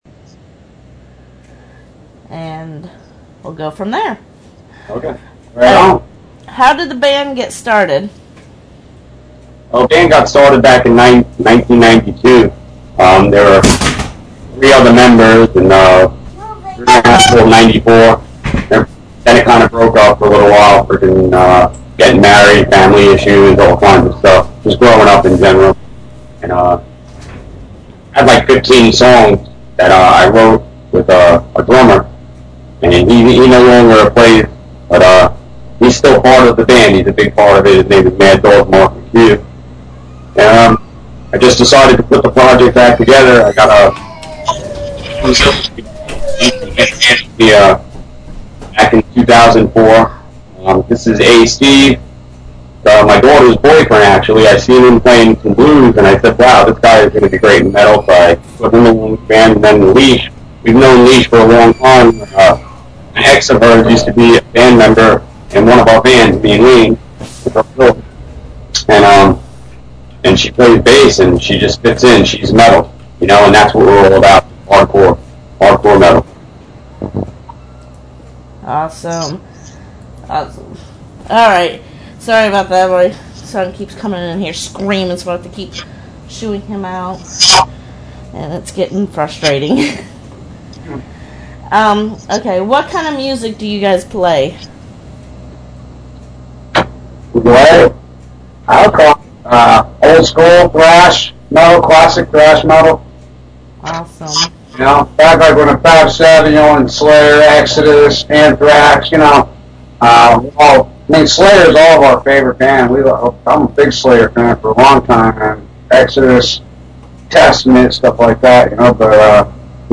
Vital-Pain-Interview.mp3